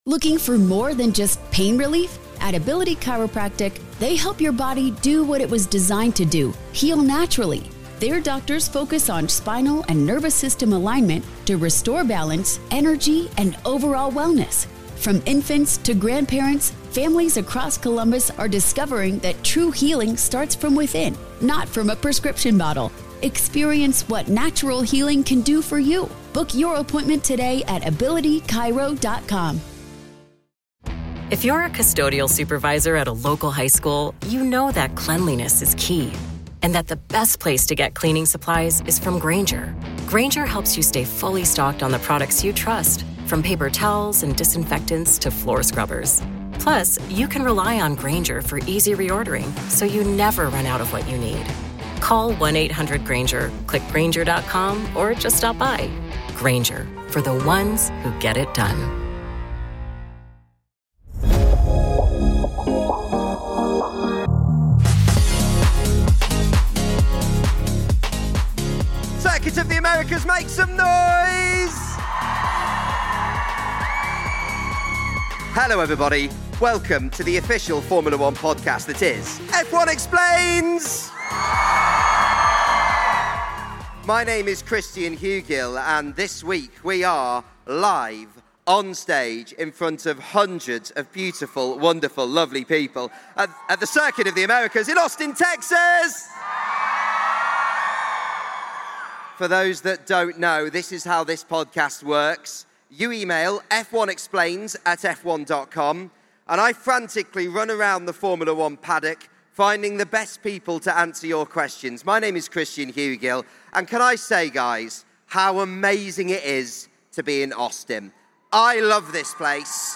David Coulthard + Mika Hakkinen explain F1 team mate title battles - LIVE at the US Grand Prix